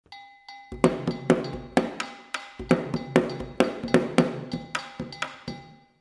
Kinka An Anlo-Ewe Secular Dance-Drumming
Gankogui (bitonal bell)
Atsimevu (lead drum)
Sogo (support drum)
Kidi (support drums)
KINKA DRUM MUSIC